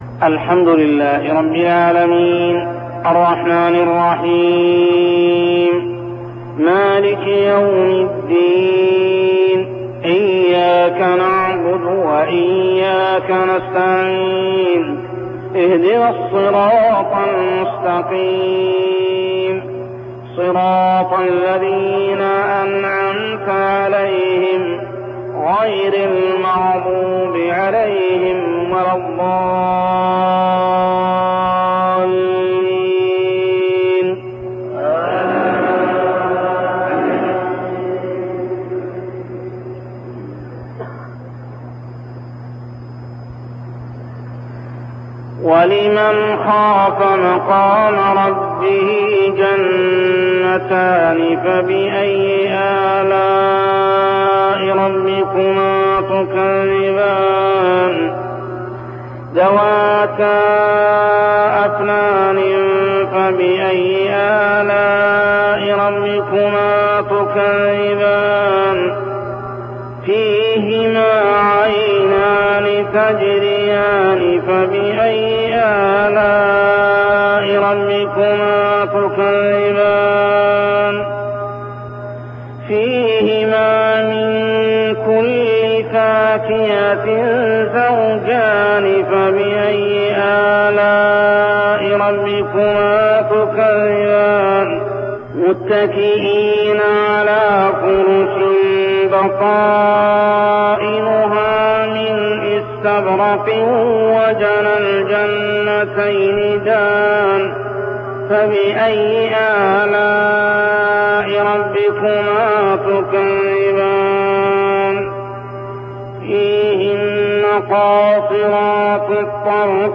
تلاوة من صلاة الفجر لخواتيم سورة الرحمن 46-78 عام 1402هـ | Fajr prayer Surah Ar-Rahman > 1402 🕋 > الفروض - تلاوات الحرمين